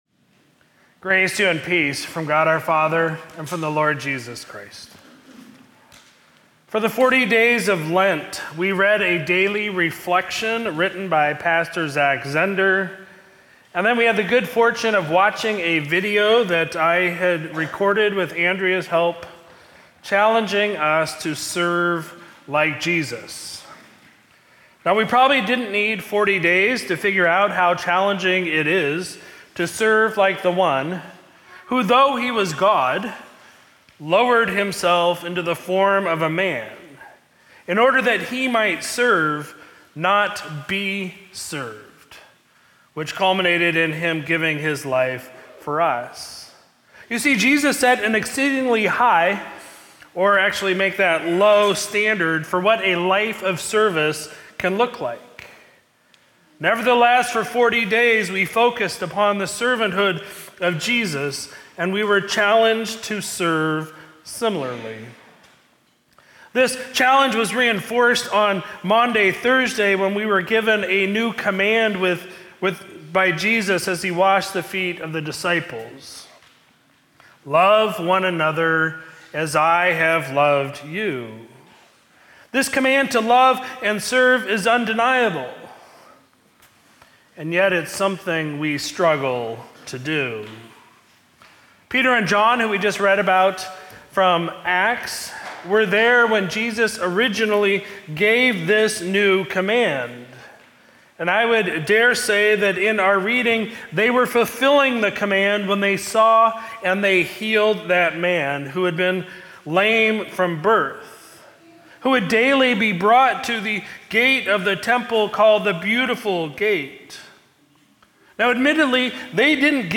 Sermon from Sunday, April 14, 2024